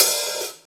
paiste hi hat3 half.wav